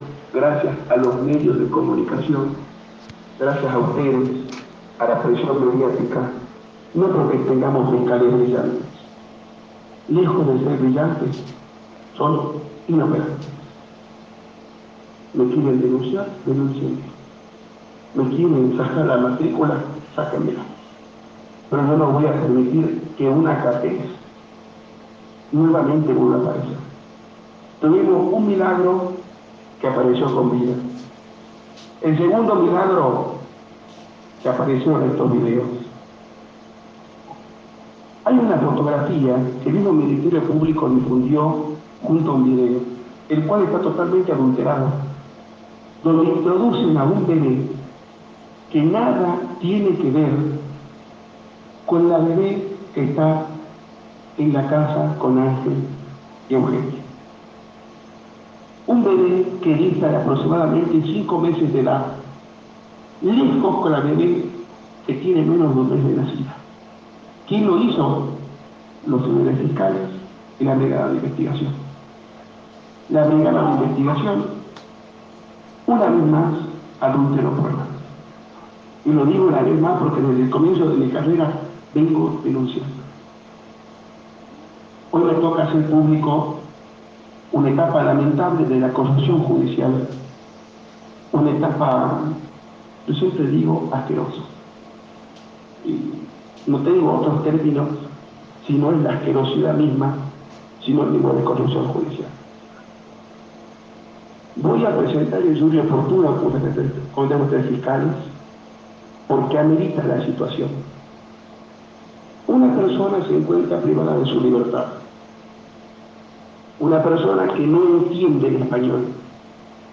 brindó una conferencia de prensa donde expuso pruebas fotográficas y fílmicas.